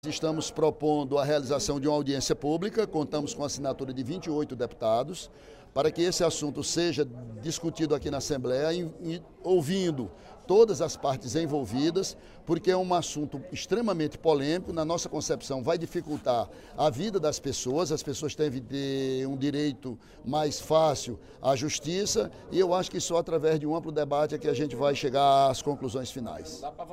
O deputado Ely Aguiar (PSDC) questionou, durante o primeiro expediente da sessão plenária da Assembleia Legislativa desta quinta-feira (06/07), a proposta do Tribunal de Justiça do Estado (TJCE) de fechar comarcas no interior do Estado.